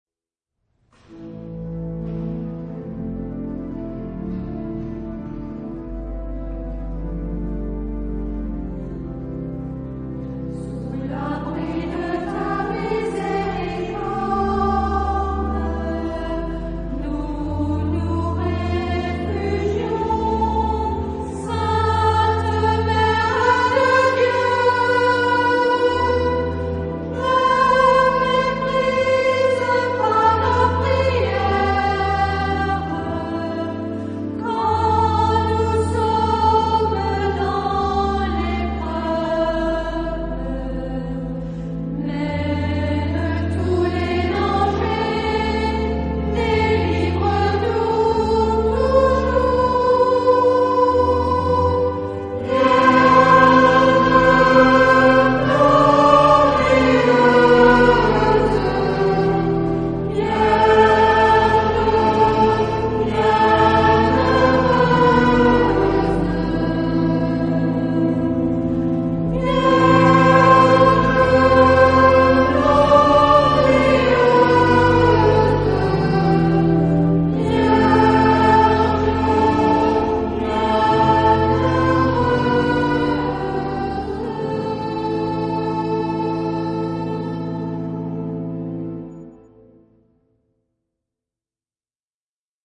Antienne mariale
Genre-Style-Form: Prayer
Mood of the piece: trusting
Type of Choir:  (1 unison voices )
Instruments: Organ (1)
Tonality: A modal